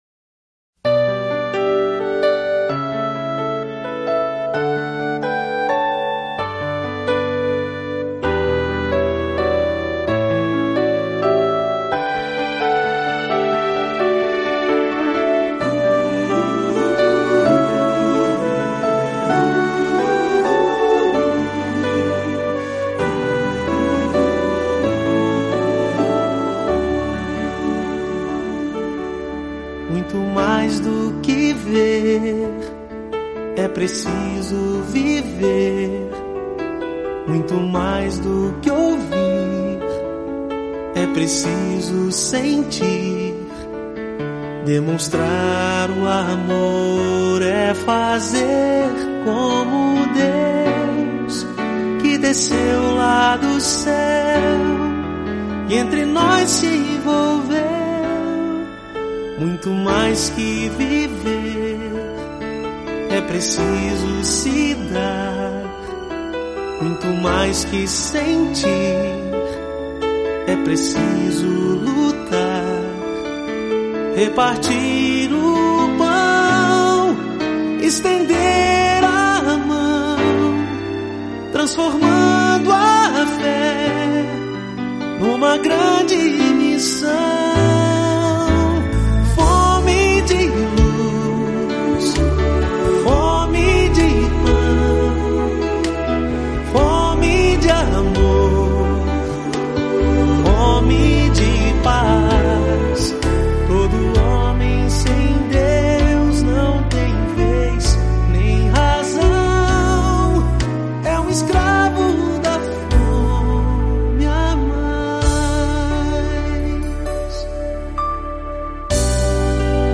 Em diversas oportunidades, participei, ao vivo, de programas da 'Rádio Você', emissora que fazia parte do grupo jornalístico 'O Liberal', de nossa cidade, Americana. O nome desse programa ainda é 'Mensagem de Paz', mantido pelas Igrejas Presbiterianas da região.